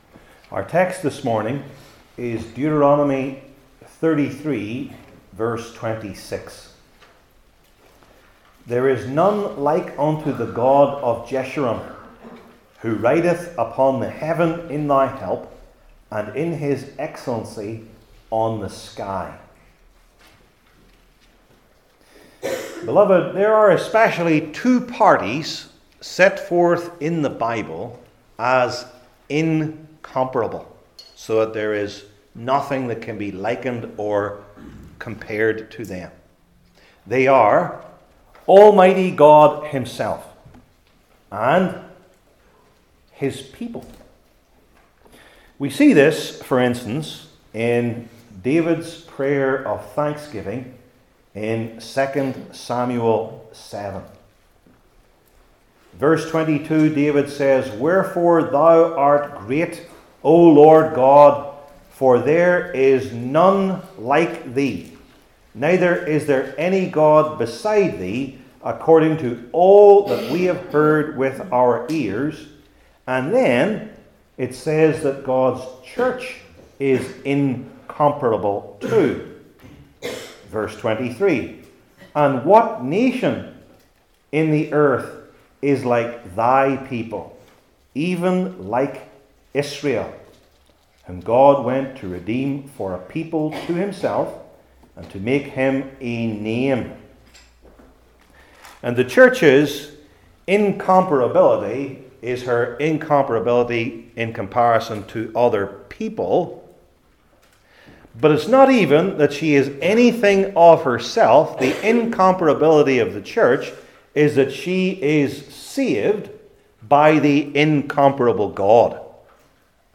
Old Testament Individual Sermons I. In the Old Testament II.